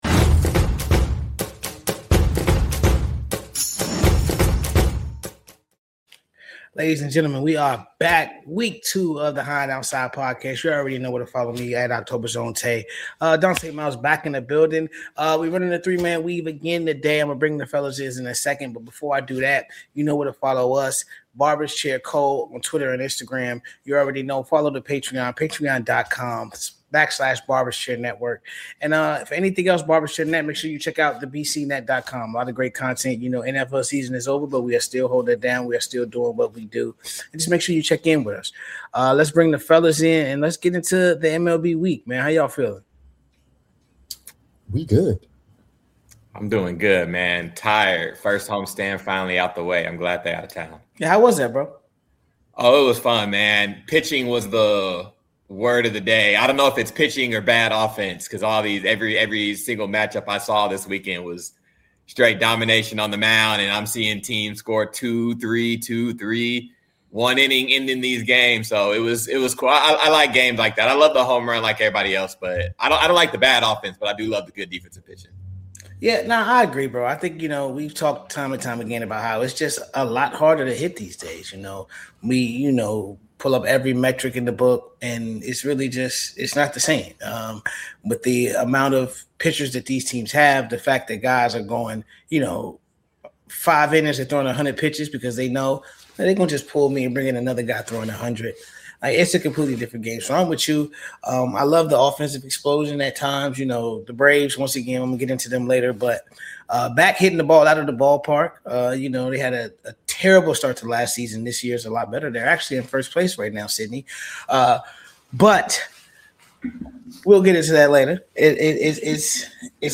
The Trio is back to talk MLB for a quick 30 minute roundtable.